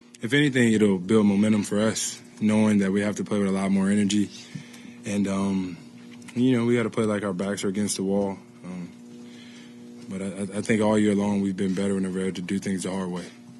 In the post-game press conference after the Game 4 loss, Jimmy Butler said “we’ll be okay.”